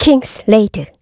Ripine-Vox_Kingsraid_kr.wav